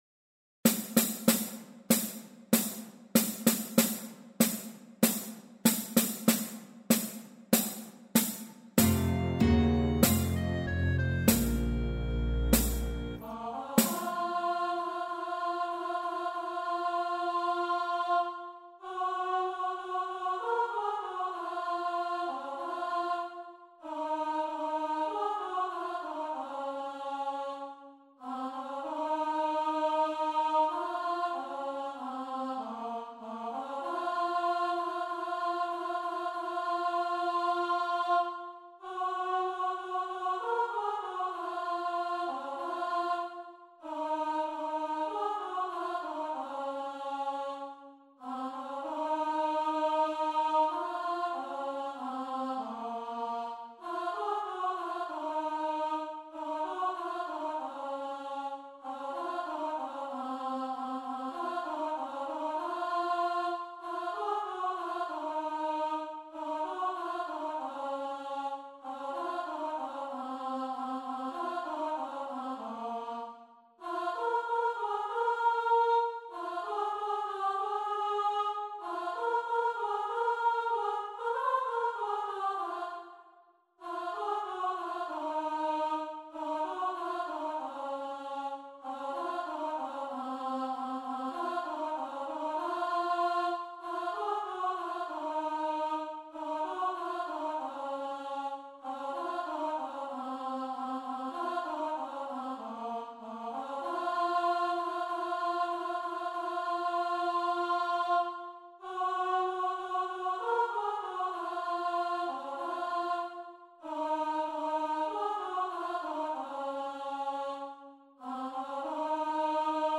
It’s the same verse sung three different ways.
When you start the backing track you will hear some clicks and then a short introduction to allow you to be ready for the actual singing.